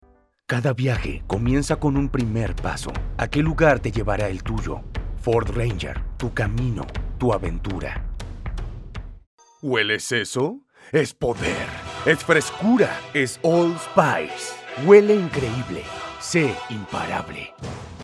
Distintas tonalidades y estilos de voz
Español Neutro
Poderosa / Épica
podedora-epica.mp3